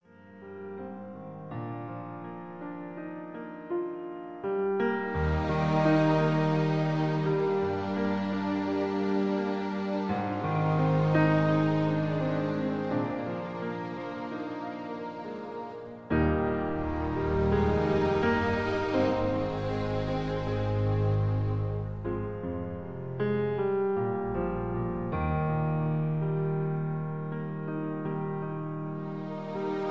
(Key-E, Tono de E) Karaoke MP3 Backing Tracks